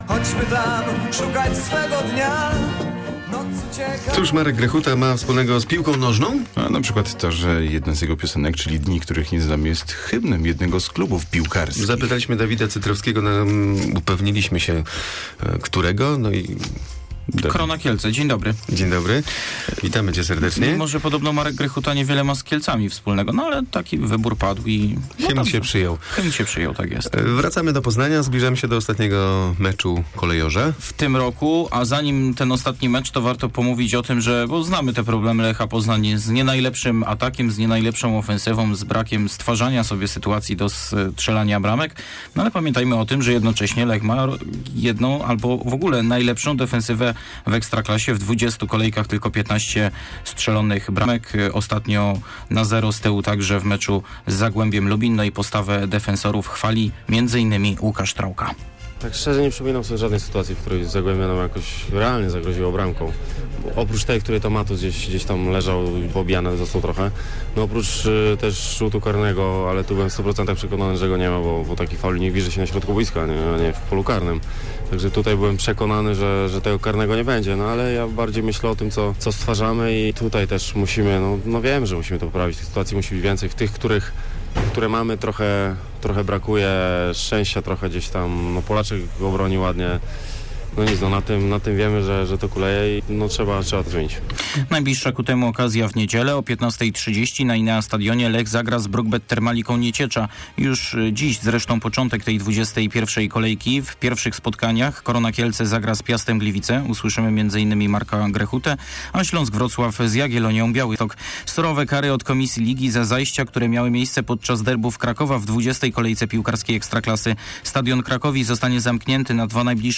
15.12 serwis sportowy godz. 7:45